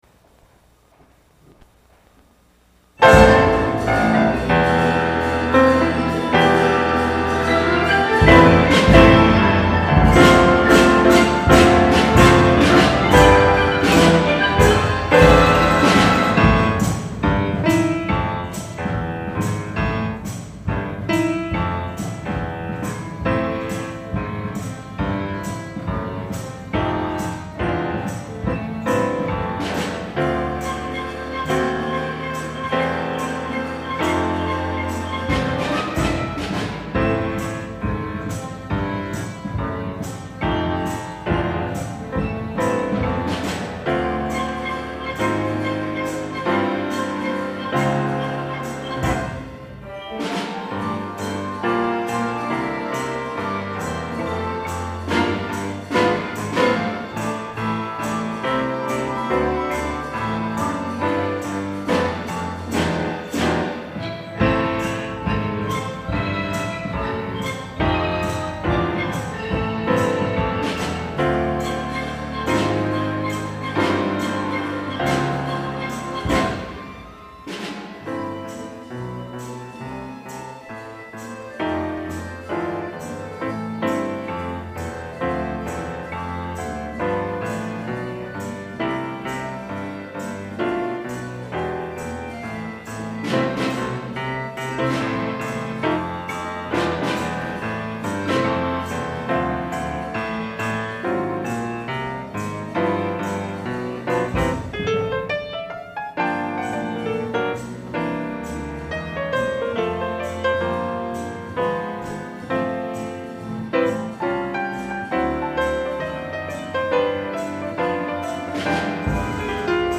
今年のリーダーは少し難しいジャズの曲にチャレンジし、今まで大切に学習を積み重ねてきました。